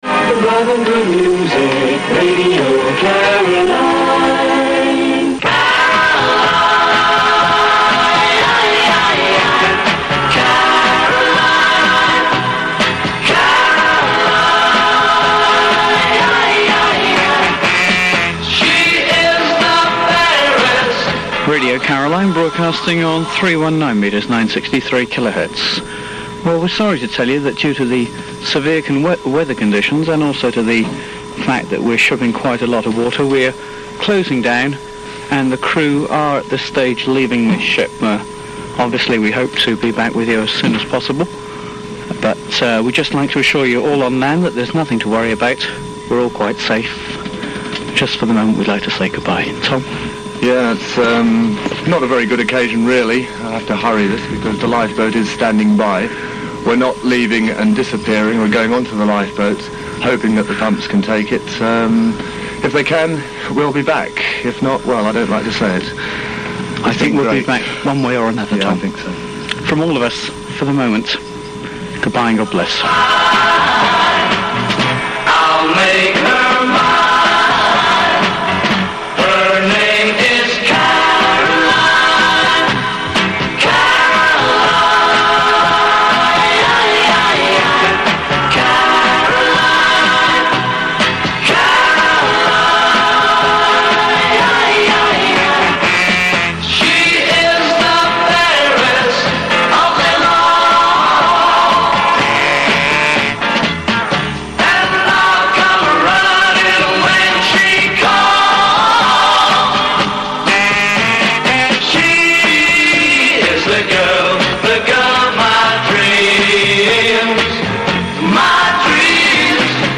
On this audio, hear the calmness on-air and the eerie final farewell. An empty channel on Medium Wave sounds more ghostly than FM.